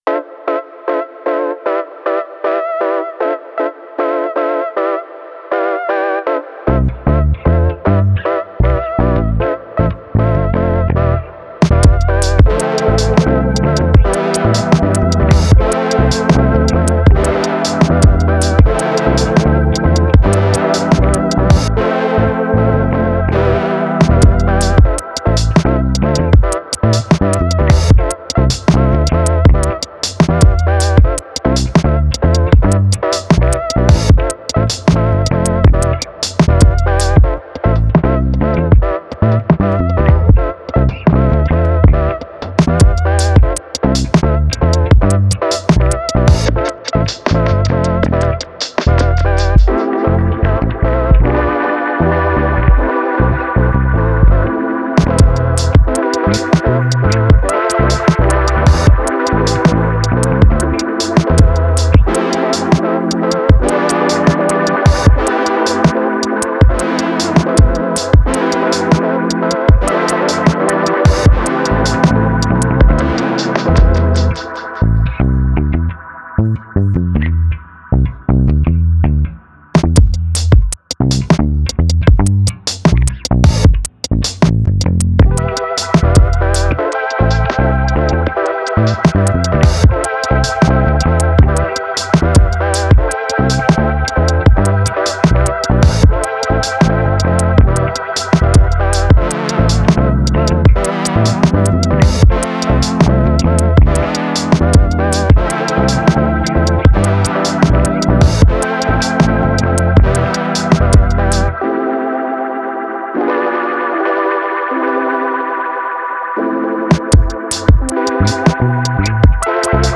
Laidback beats carve out a quirky groove.